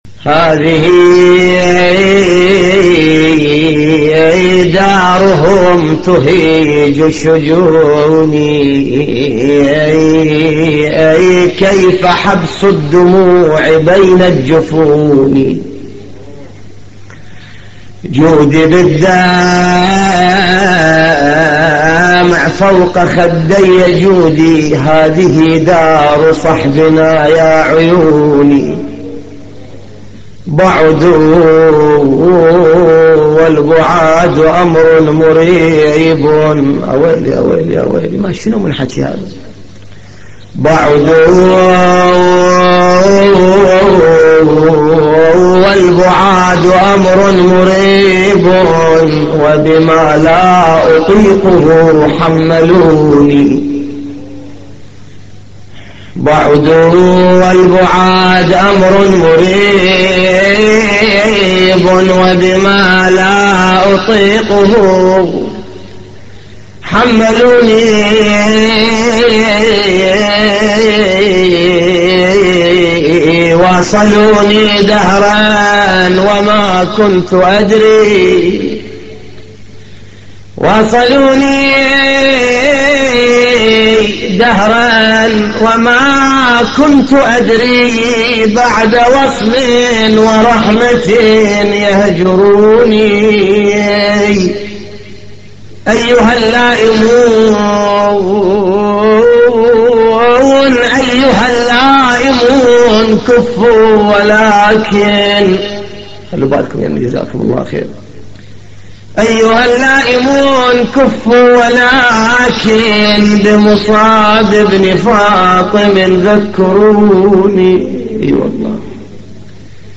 للتحميل This entry was posted in نواعي